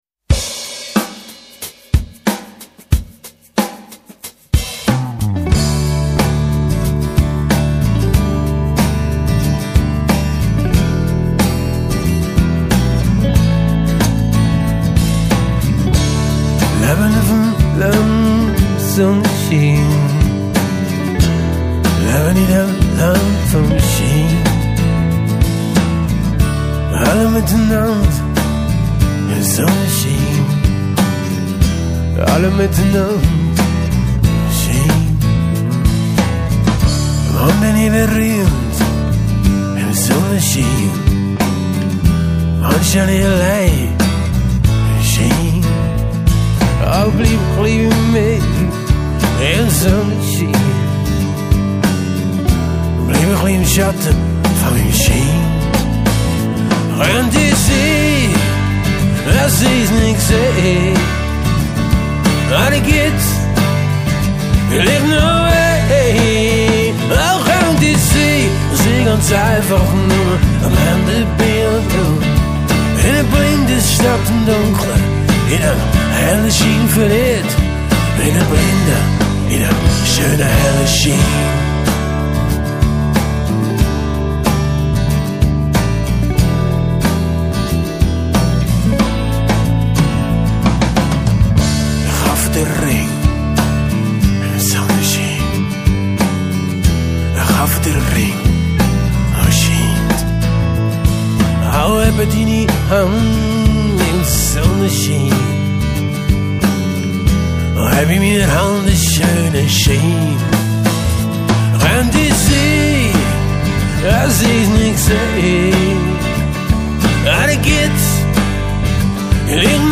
Gitarren
Schlagzeug
Bass, Harmoniegesang
Gesang